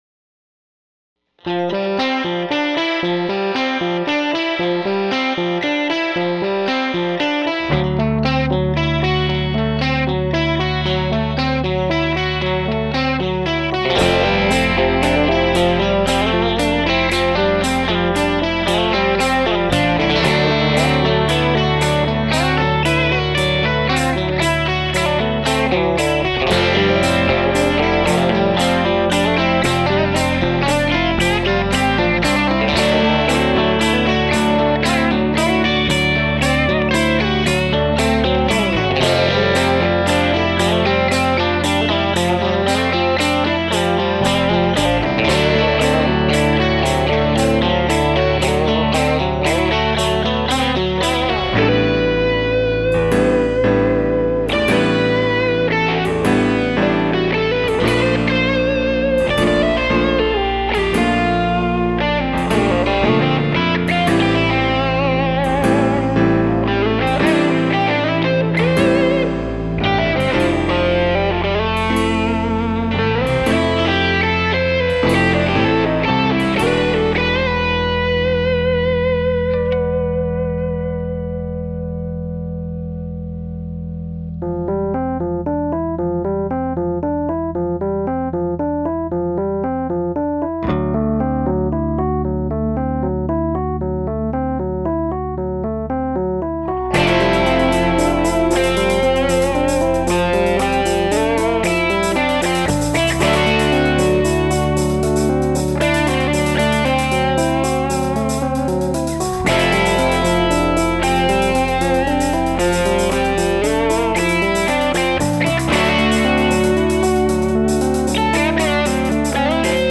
Unless otherwise illustrated, the MP3 tracks above are all recorded with the guitars ('98 Fender '57 RI USA Strat or '68 Gibson 335) straight into the Tweed Deluxe - with the exception of
Monk (Uni-Vibe, CS-505, Fuzz Face), Savoy Zep (Vox Wah), and